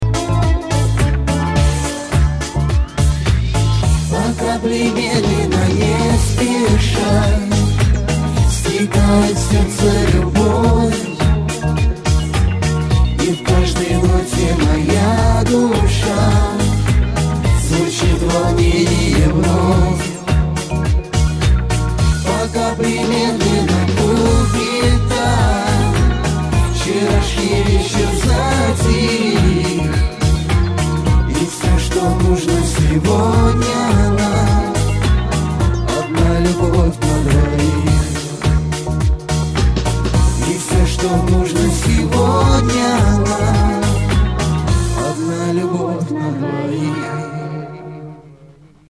• Качество: 320, Stereo
спокойные
дуэт
русский шансон
мужской и женский вокал